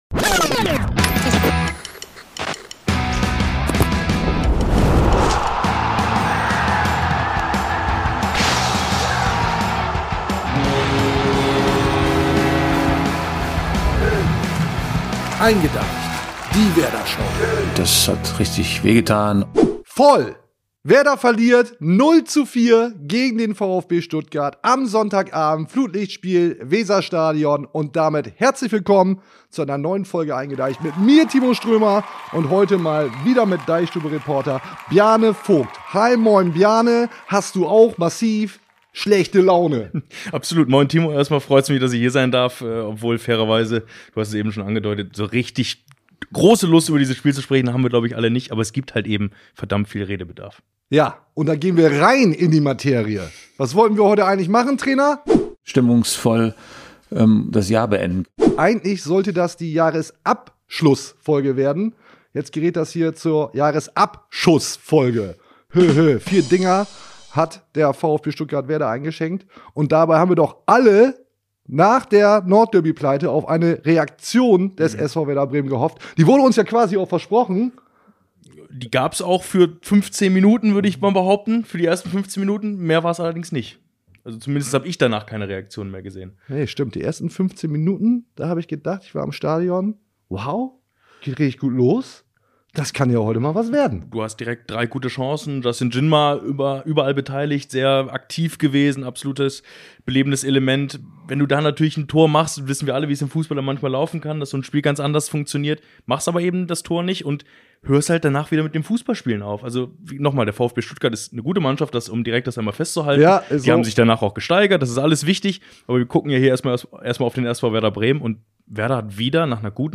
In der Werder-Podcast-Show eingeDEICHt, gesendet aus dem DeichStube-Office, erwartet Euch darüber hinaus wie immer eine Vollgas-Veranstaltung vollgestopft mit den Themen, die die Fans des SV Werder Bremen beschäftigen.
Alberne Einspieler, allerlei Blödsinn, schlechte Wortwitze, dumme Sprüche, manchmal Werder-Expertise.